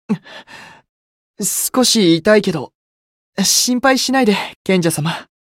觉醒语音 少し痛いけど、心配しないで賢者様 媒体文件:missionchara_voice_111.mp3